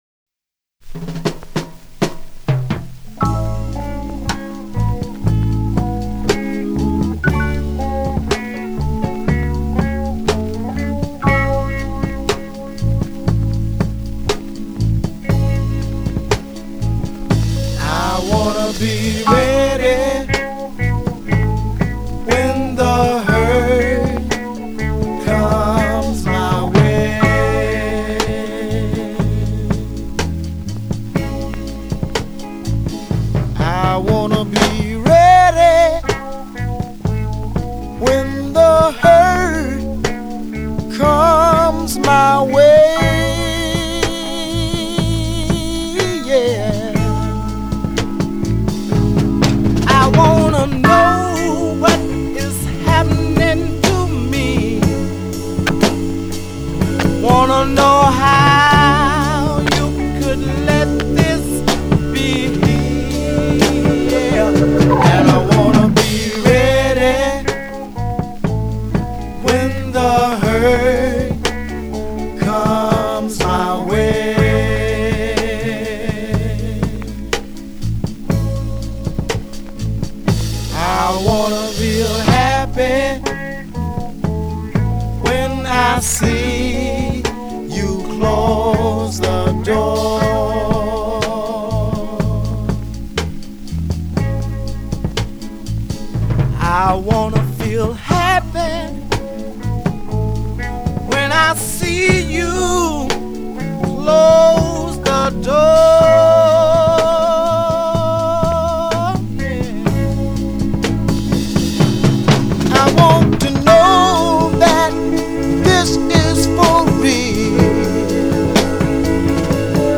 It took its time and didn’t care if it was damaged.
A ghostly, mysterious transporter.